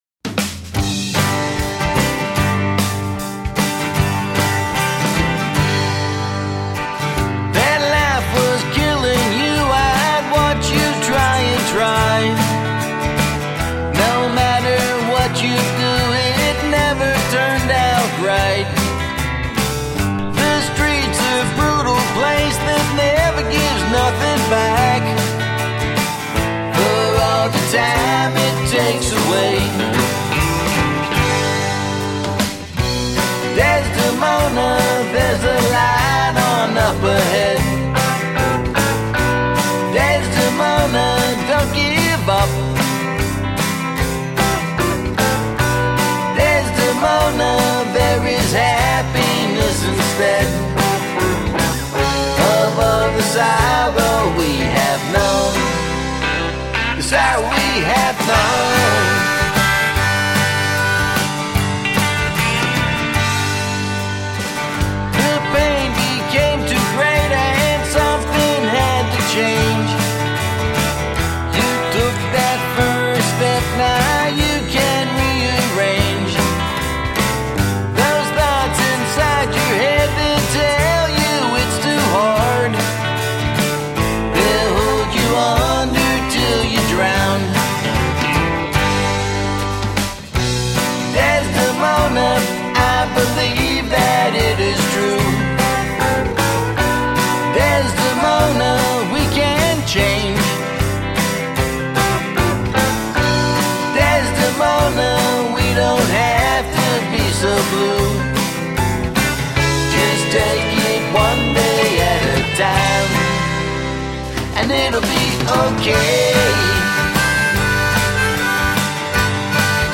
Roots rock meets contemporary folk.
Tagged as: Alt Rock, Folk